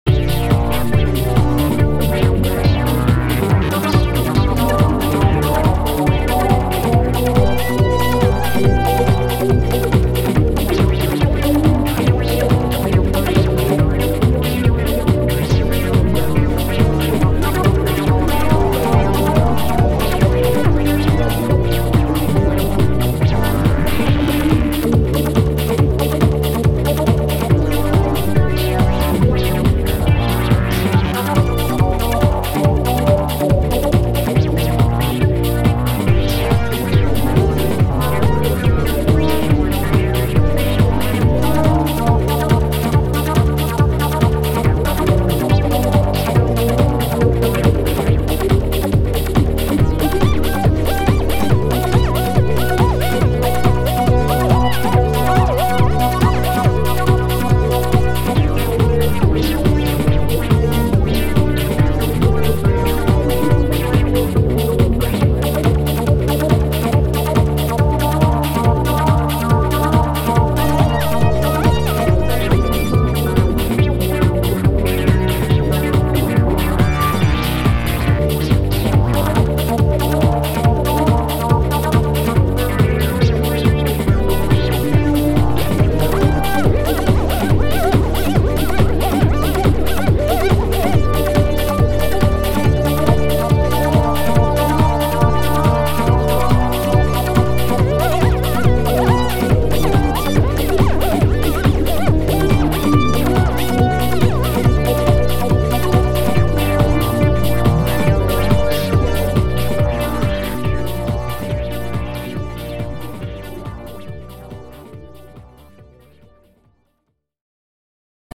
On obtient comme résultat une musique assez inhabituelle, structurée mais sans thème répétitif.